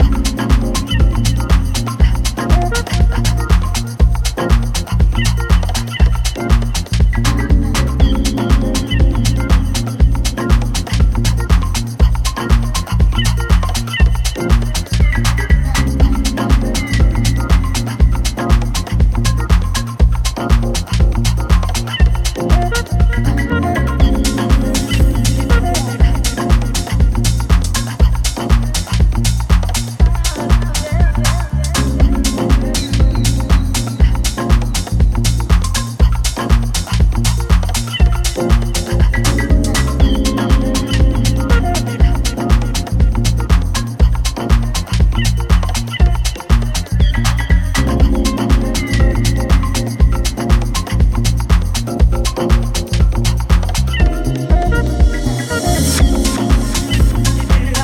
ここでは、ジャジーで豊潤なメロディーが情感を駆り立てる、ウォームでグルーヴィーなディープ・ハウス群を展開。